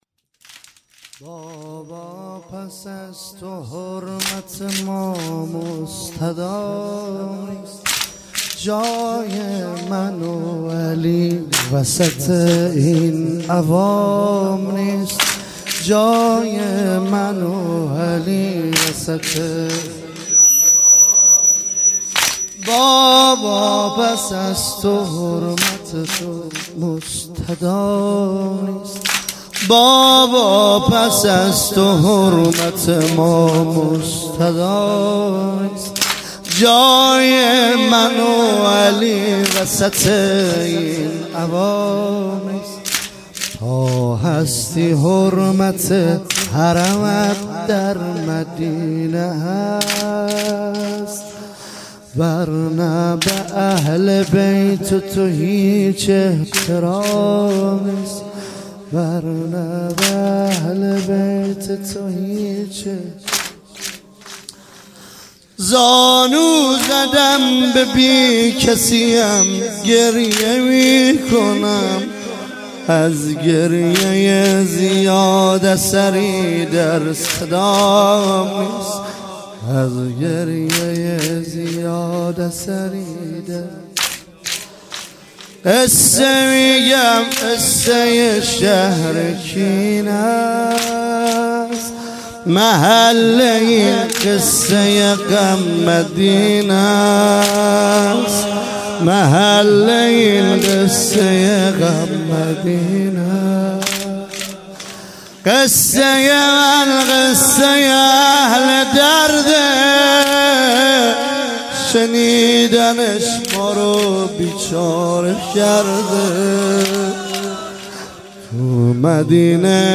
هیئت متوسلین اسلام آباد گرگان ۹۶/۱۱/۰۳ - واحد - بابا پس از تو حرمت